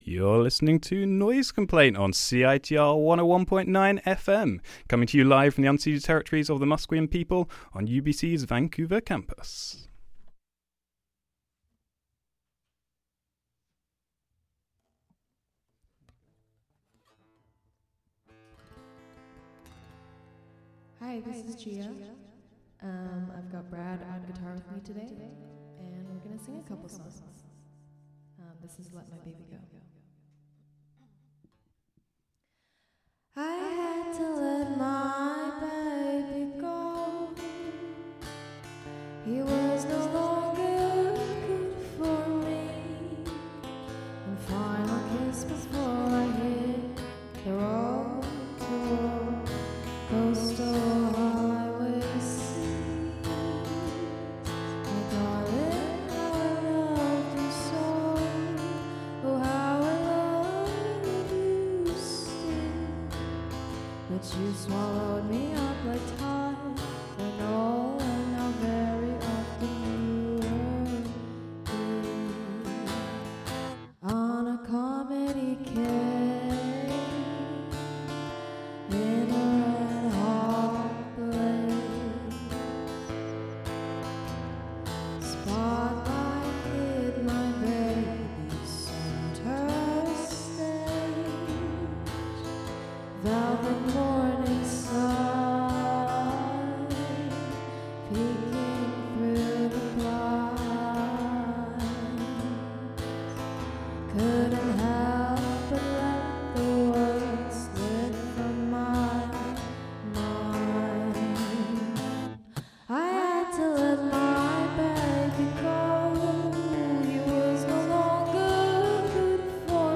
Live in CiTR Lounge